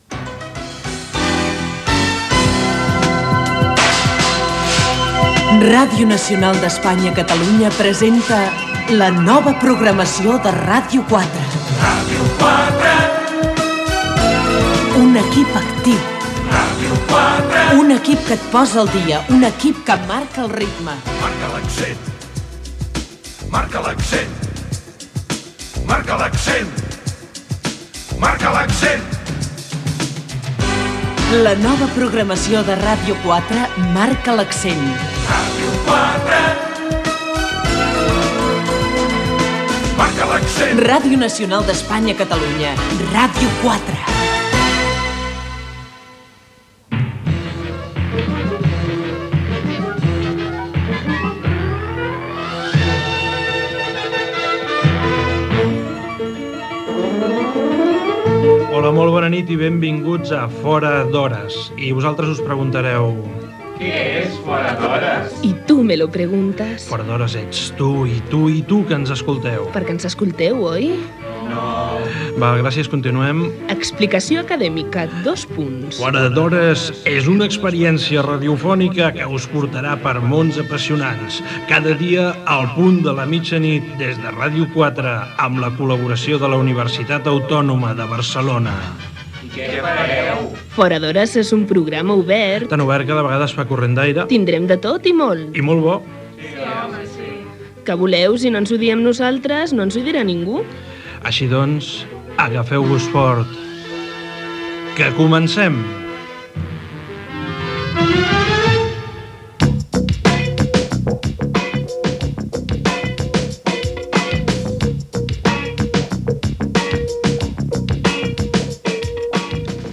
Entreteniment
Programa fet pels alumnes de la Facultat de Ciències de la Comunicació de la Universitat Autònoma de Barcelona.